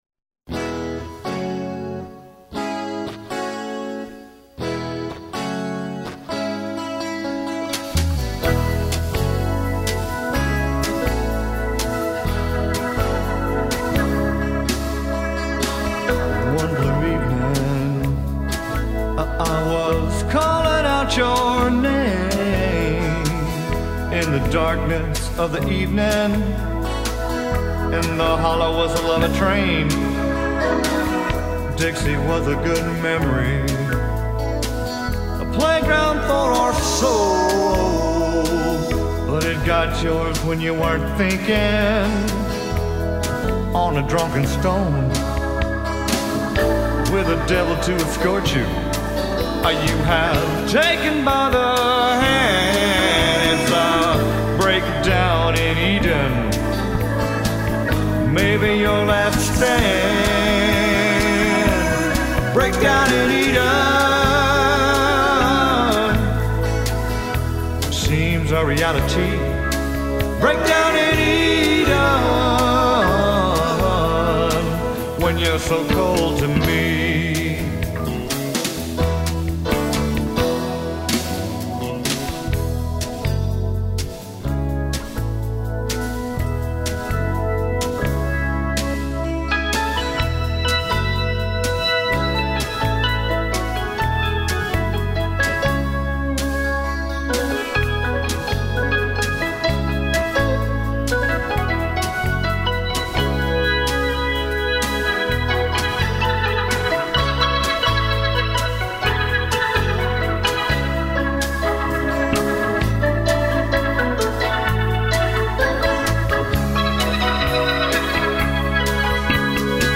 open up a four barrel of V-8 ROCK~A~BILLY RHYTHM & BLUES.